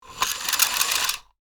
Throttle Controller Rope, Pulling Fast 2 Sound Effect Download | Gfx Sounds
Throttle-controller-rope-pulling-fast-2.mp3